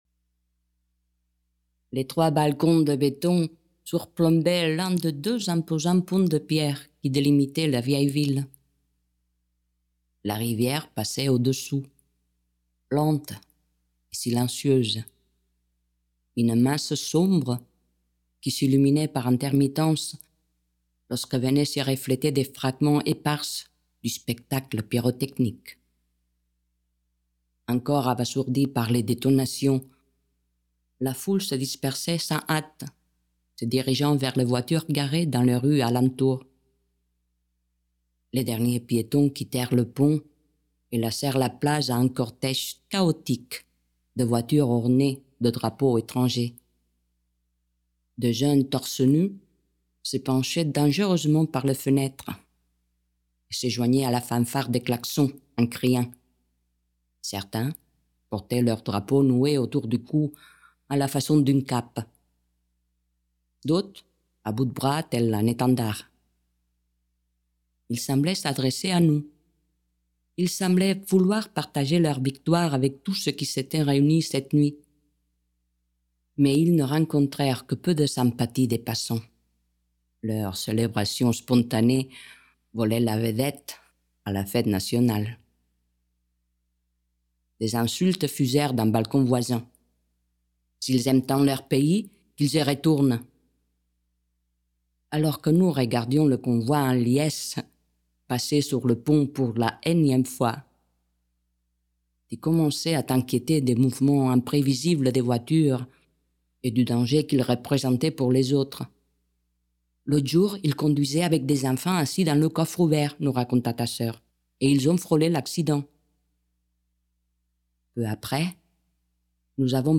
Installation consisting of twelve framed inkjet prints, spoken word on car speakers, car radio, battery, variable dimensions, audio: 12' 3'', 2019
Some of the images refer to details of the spoken text, others allude toward undisclosed narratives. The sound piece is broadcast by car radio speakers connected to a battery, all placed on the grid of the drain tank.